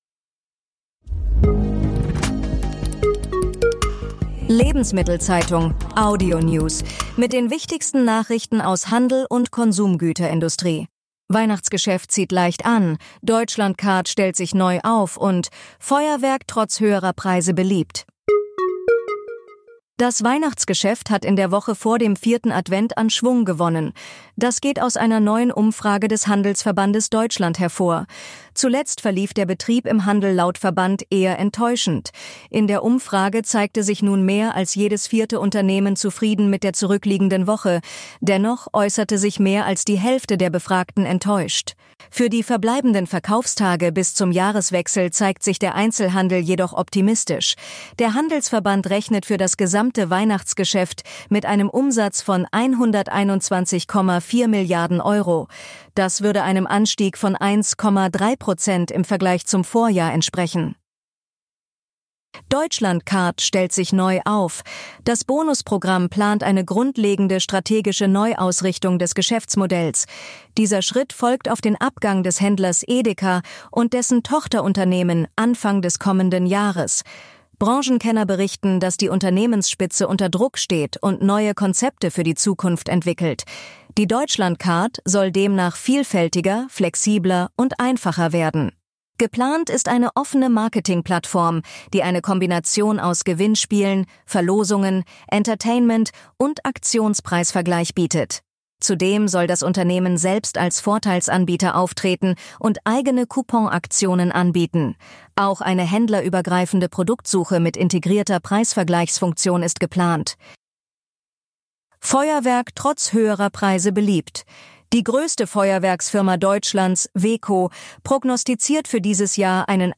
Tägliche Nachrichten